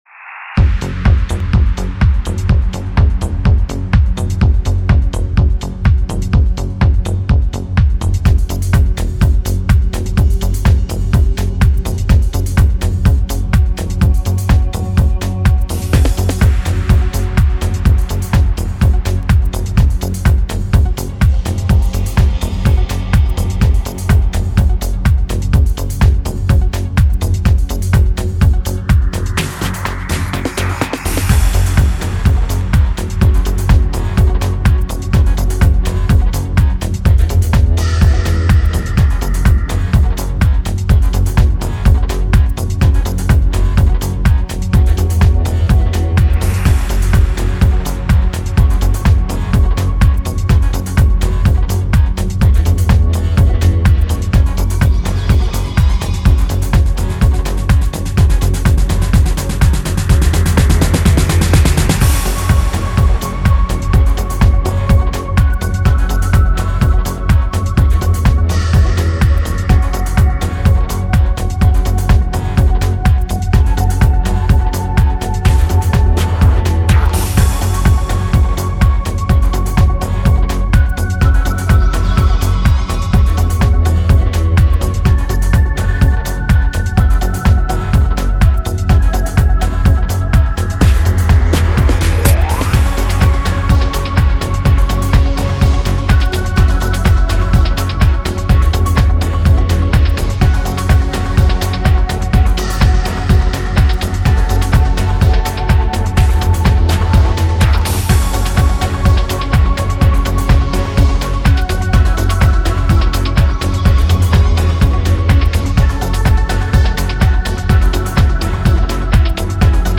Techno and house music, deep bass and fast beats.
Techno and house music podcast playing up to date tracks.